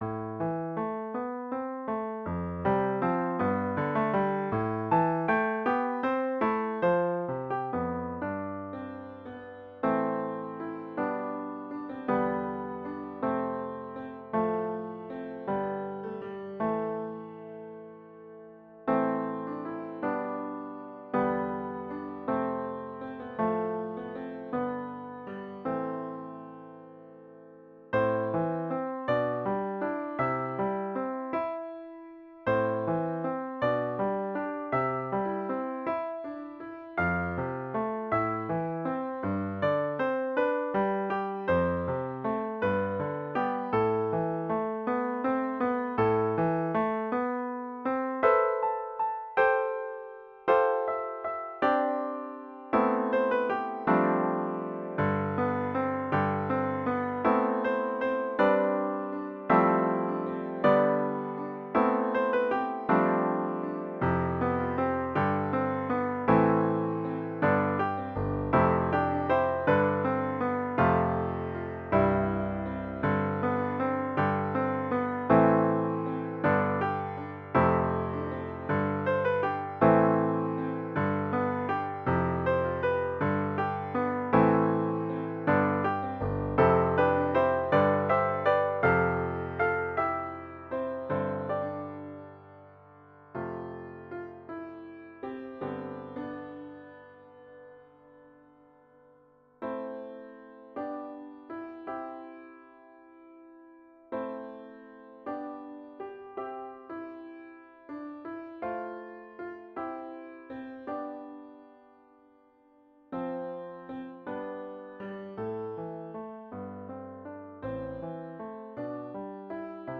Arranged in the key of A minor.
Voicing/Instrumentation: Vocal Solo